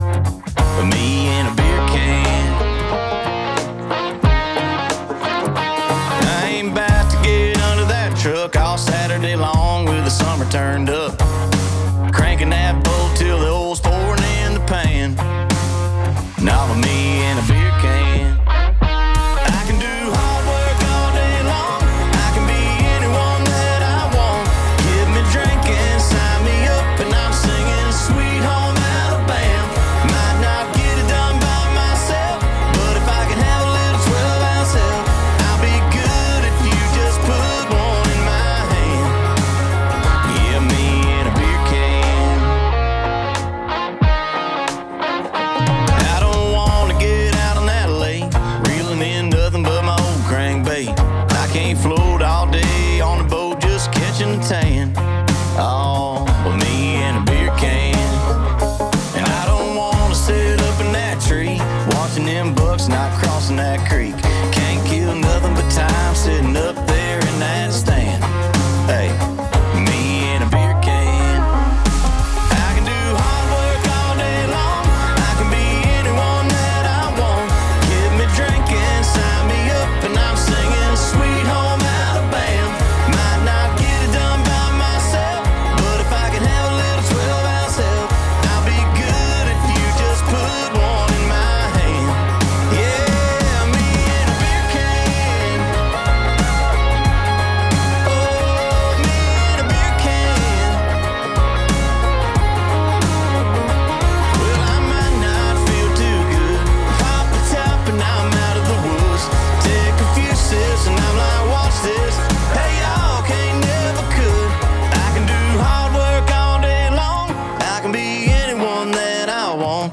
Hot Country Hits from the 90s to Now!